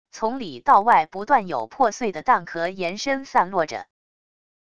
从里到外不断有破碎的蛋壳延伸散落着wav音频生成系统WAV Audio Player